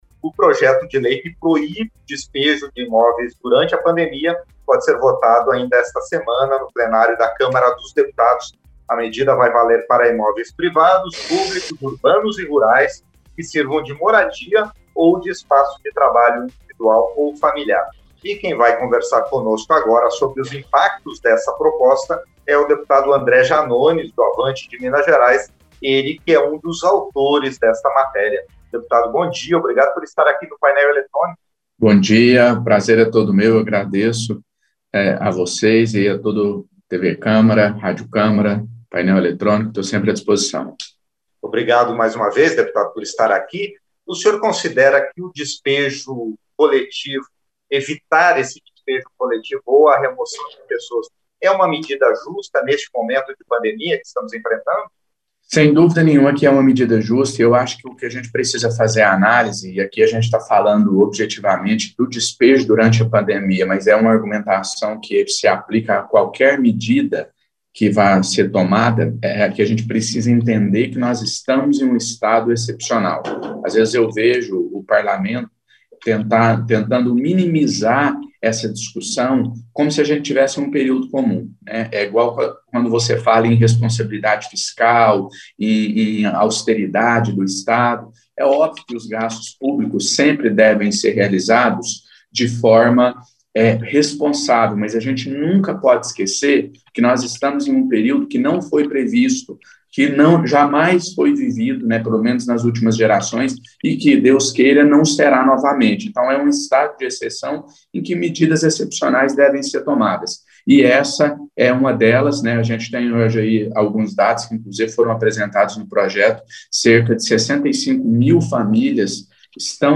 Entrevistas - Dep. André Janones (AVANTE-MG)